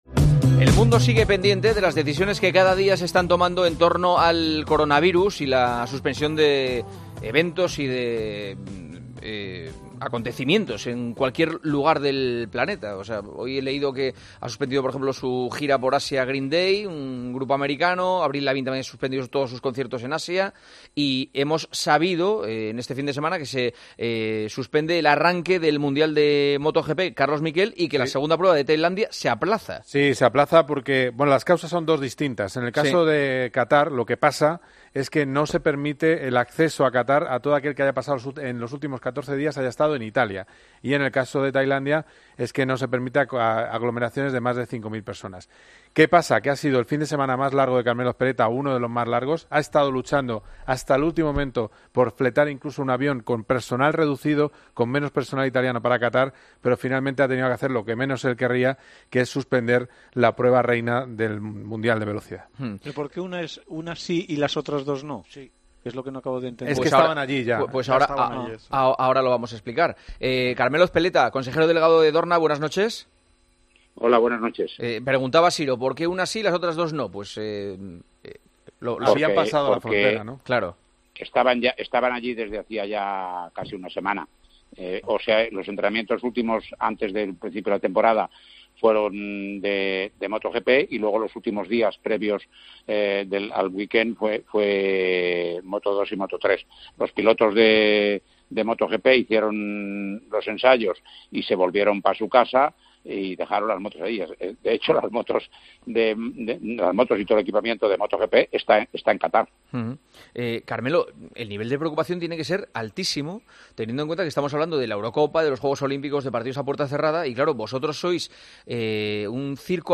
El coronavirus ha obligado a tomar medidas en MotoGP y en la Euroliga. Hablamos con Jordi Bertomeu y con Carmelo Ezpeleta.